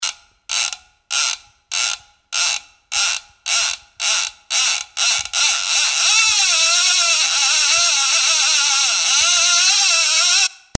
Wem das zu heftig ist, nimmt dies:
HardyPerfect.mp3